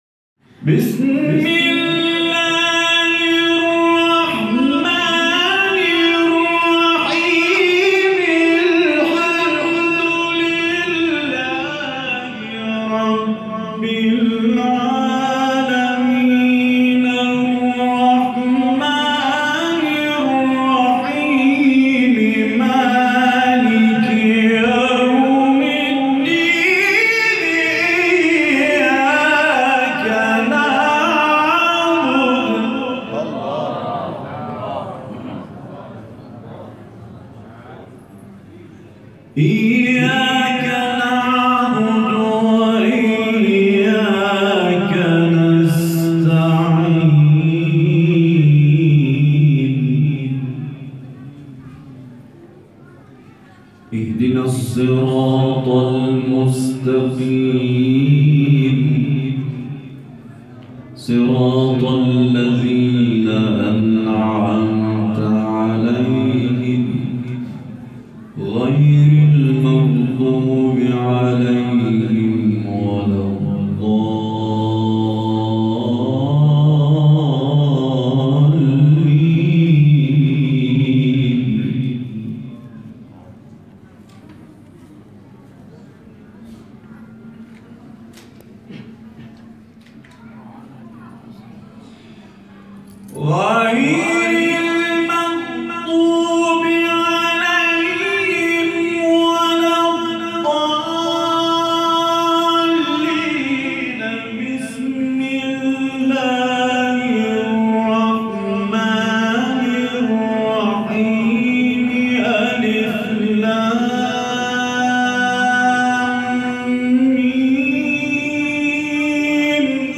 گروه فعالیت‌های قرآنی: فرازهای صوتی از قاریان ممتاز کشور ارائه می‌شود.